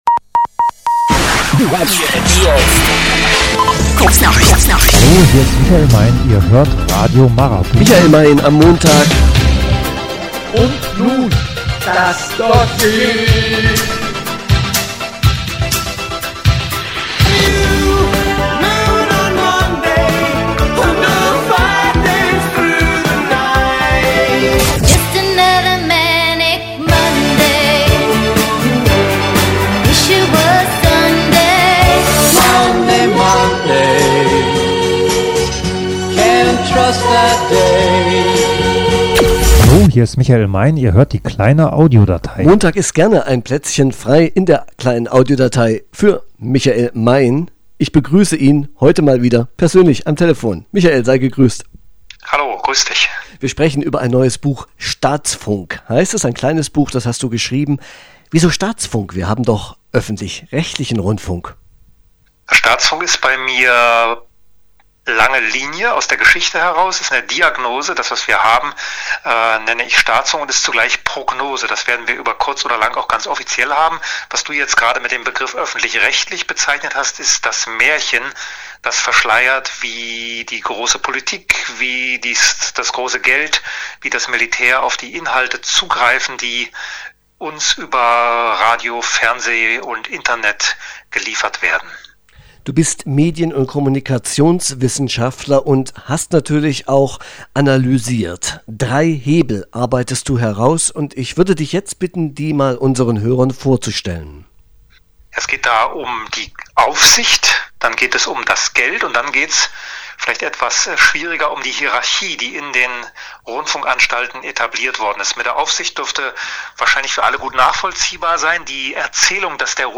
Neu: ein Vortrag mit zwei Lesestücken auf der Büchermesse Seitenwechsel.